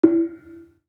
Kenong-dampend-E3-f.wav